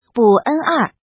怎么读
ń